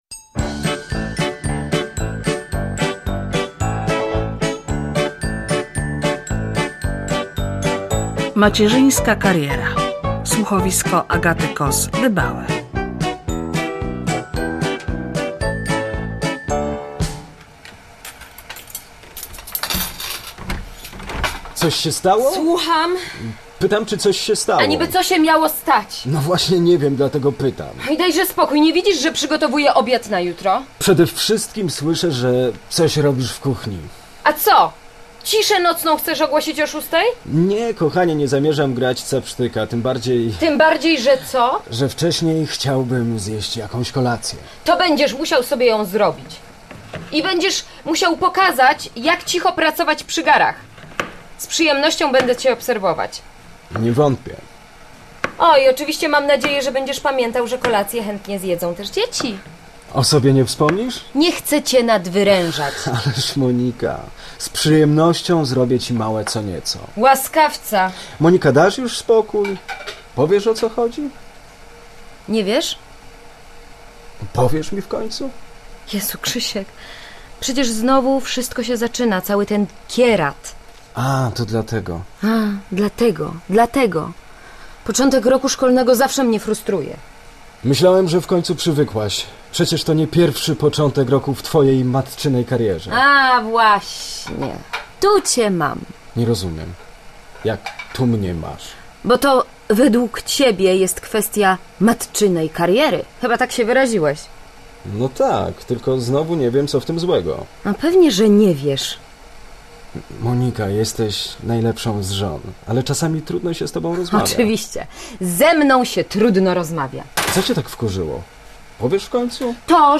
Słuchowiska Polskiego Radia Lublin